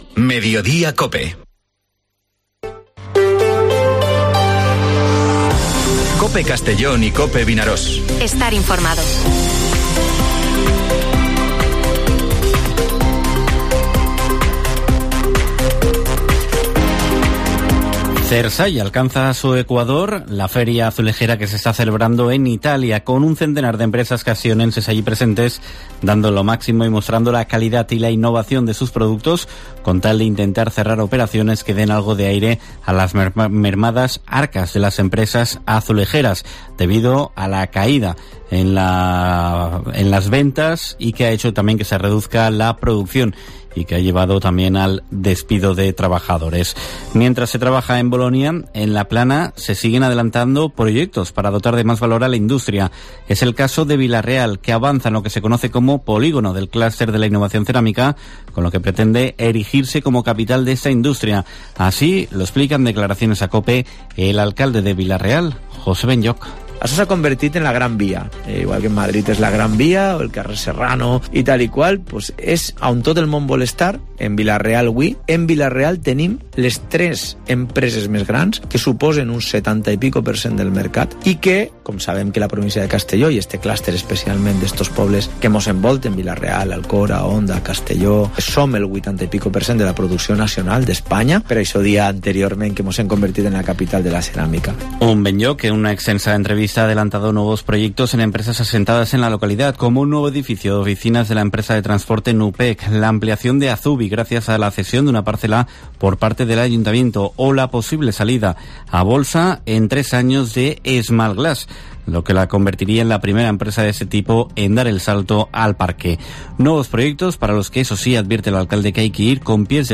Informativo Mediodía COPE en la provincia de Castellón (27/09/2023)